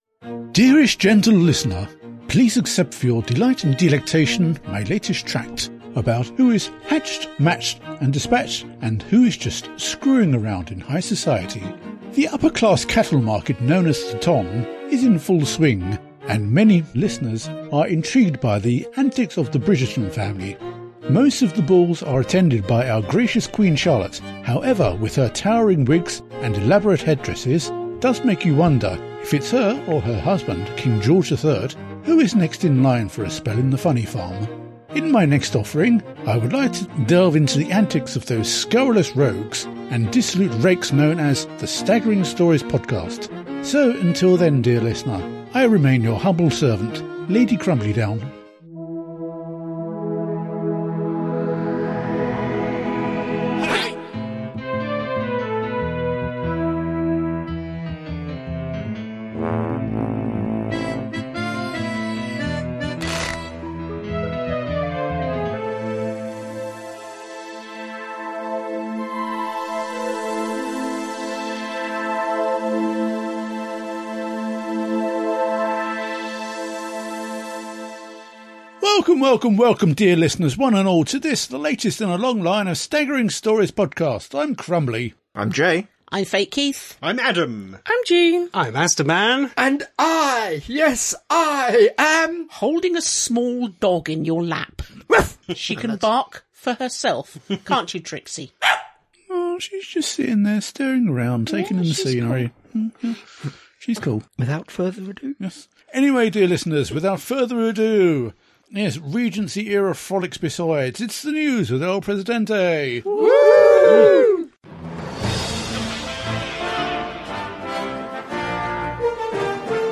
Intro and theme tune.
End theme, disclaimer, copyright, etc.